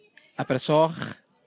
Abersoch (Welsh pronunciation: [abɛrˈsoːχ]
Pronunciation of Abersoch
Abersoch.ogg.mp3